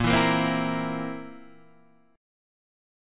A#sus2b5 chord {6 7 8 5 5 6} chord